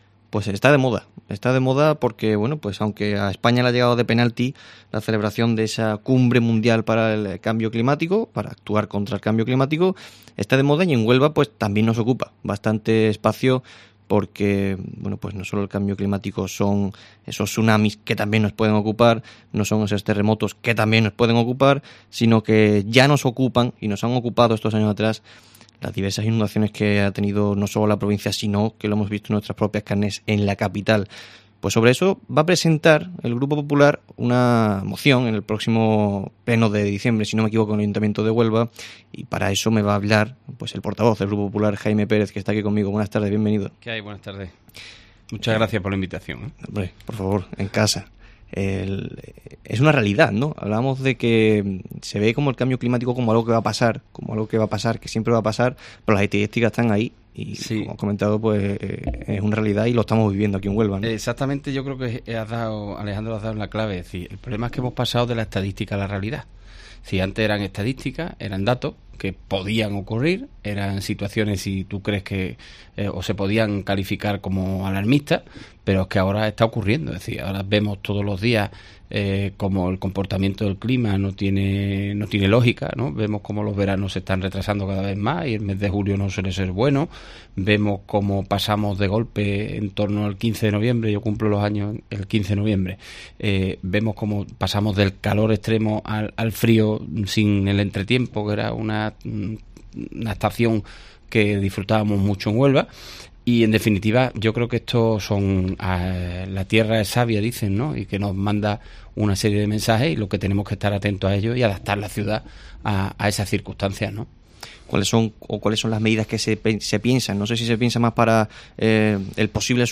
Jaime Pérez, portavoz del PP en el Ayuntamiento de Huelva, explica moción para revisar el Plan de Emergencia Municipal realtivo a las inundaciones.